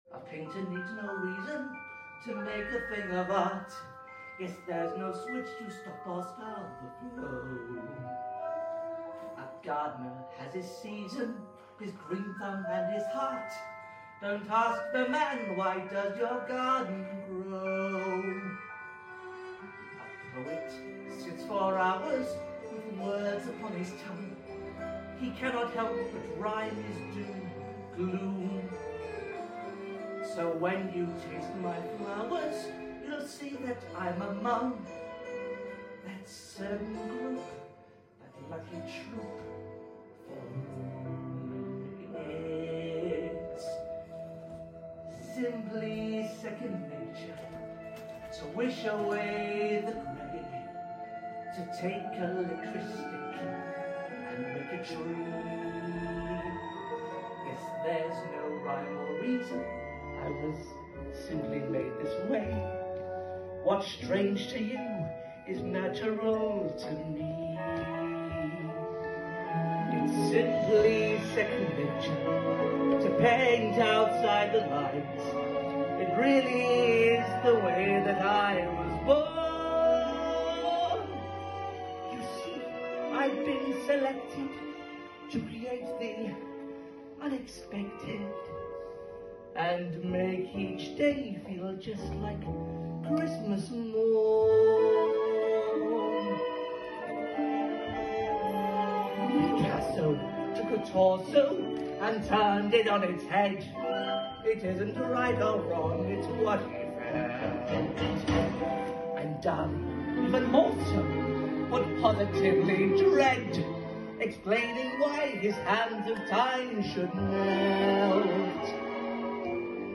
A super cut of both the videos from my performance on Saturday night!